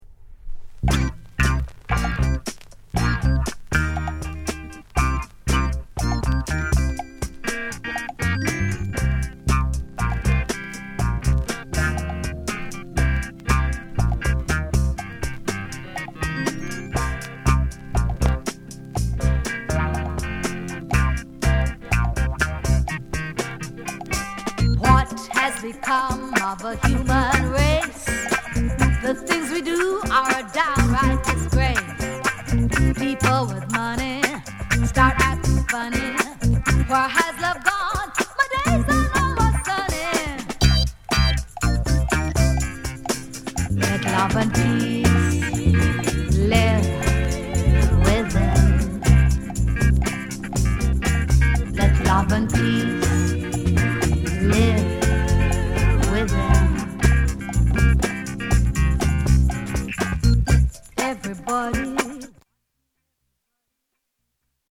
RARE FUNKY REGGAE